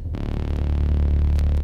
Crispy_moog.wav